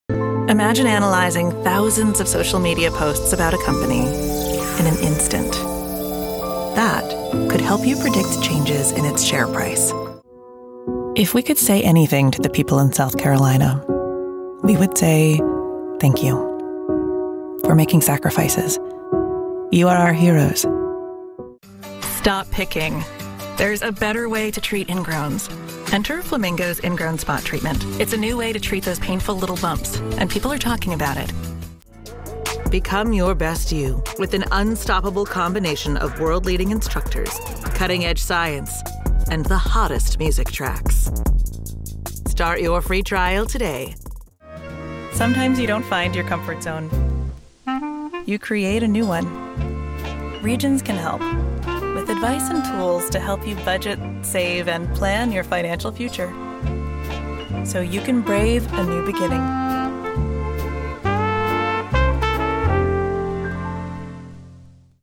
A warm, comforting, intelligent storyteller that brings a sense of passion, security, and trust to your brand.
Commercial Demo
Middle Aged
Fast Responses and Same Day Turnaround available from my professional home recording studio.
▸ My voice is natural, real, and emotionally present.
My commercial and corporate reads are conversational, emotionally intelligent, and never over-produced.
⊹ Neumann TLM103 Microphone